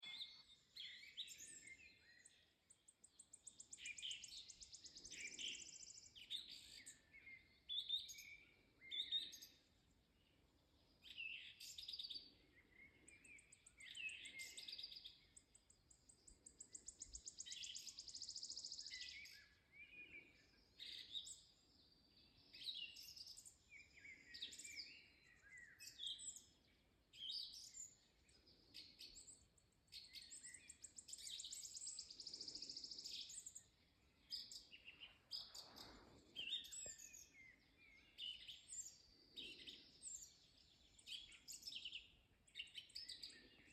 Birds -> Thrushes ->
Song Trush, Turdus philomelos
StatusSinging male in breeding season